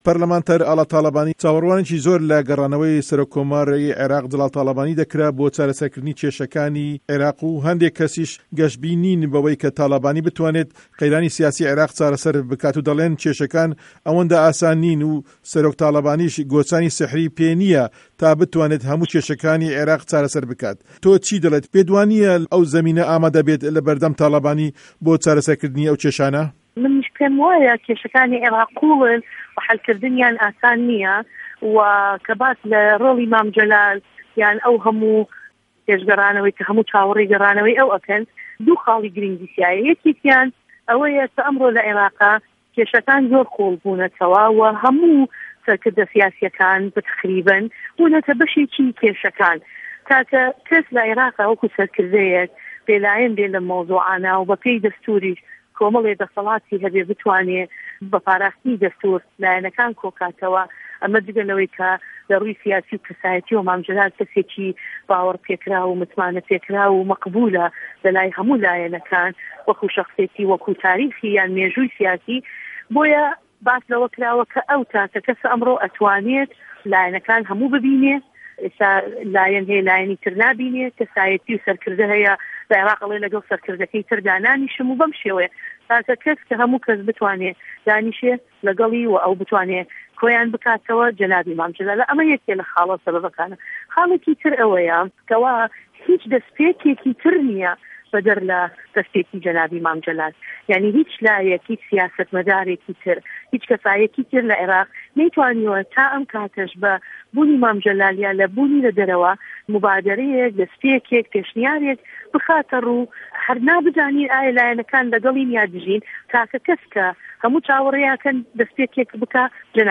وتووێژ له‌گه‌ڵ ئاڵا تاڵه‌بانی